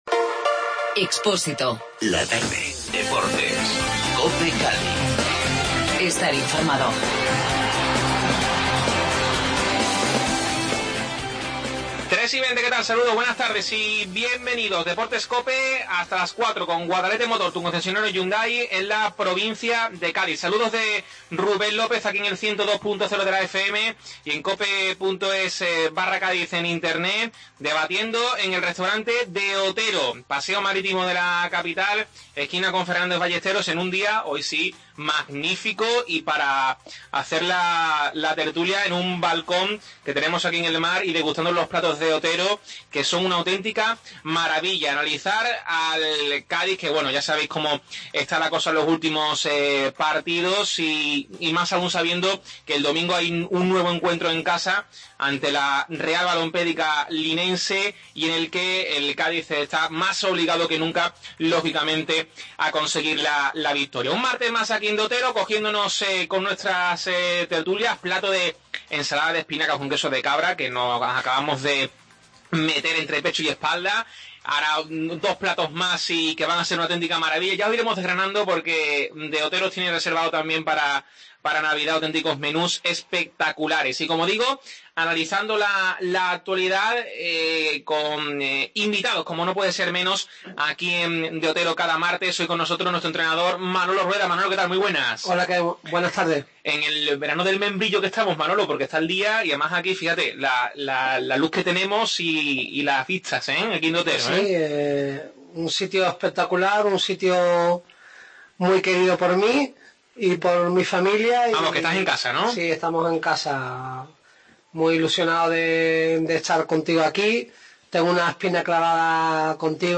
Desde el restaurante De Otero, tertulia